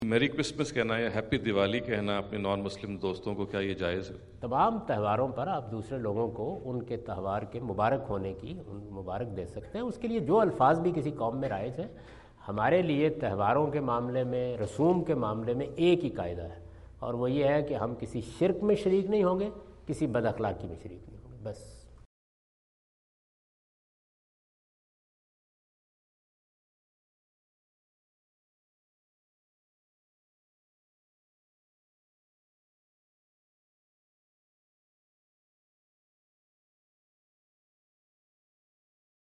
Javed Ahmad Ghamidi answer the question about "Greeting Non-Muslims on Their Festivals" asked at Aapna Event Hall, Orlando, Florida on October 14, 2017.
جاوید احمد غامدی اپنے دورہ امریکہ 2017 کے دوران آرلینڈو (فلوریڈا) میں "غیر مسلموں کو تہواروں کی مبارک باد دینا" سے متعلق ایک سوال کا جواب دے رہے ہیں۔